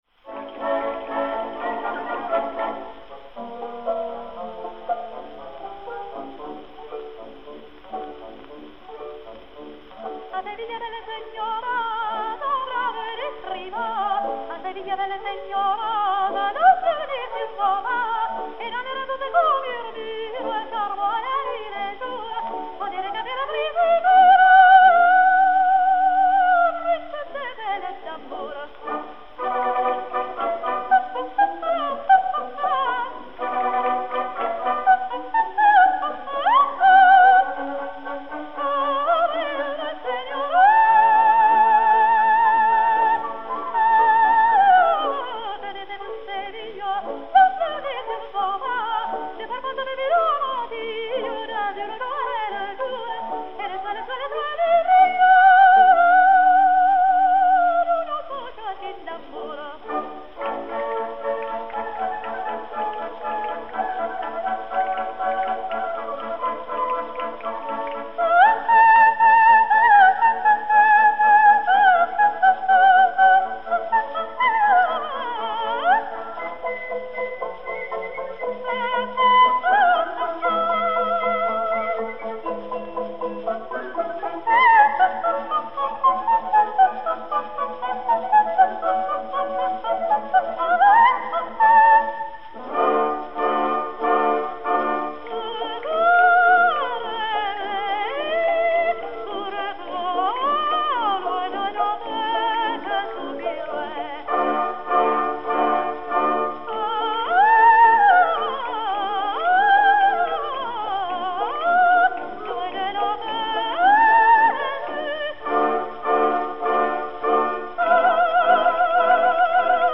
soprano
piano
B-28465, enr. à Camden, New Jersey, le 13 septembre 1923